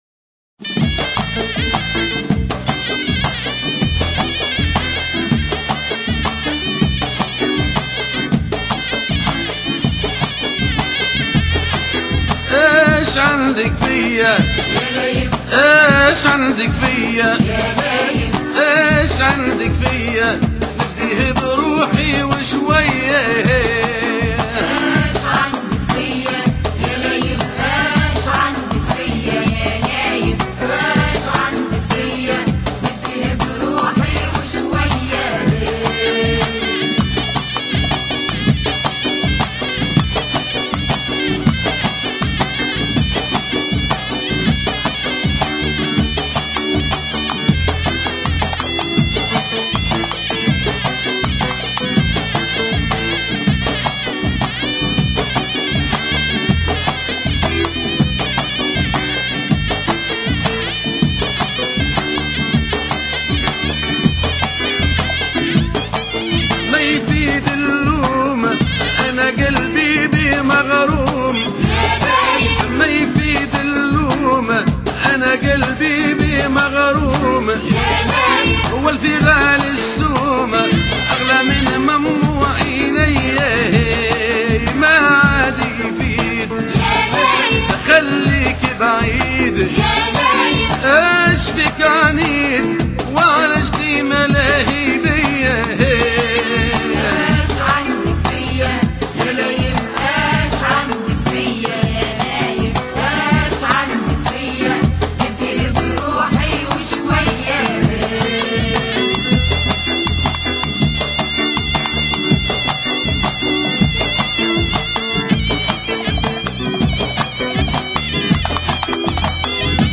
Bienvenue au site des amateurs de Mezoued Tunisien
la chanson